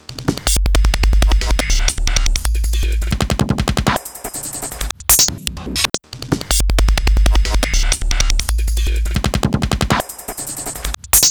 One bar track
Plock each sample at each step (16 steps)
Tempo 159
Turn Start
**Octatrack 64 breakbeat x 16 slices megabreak of doom: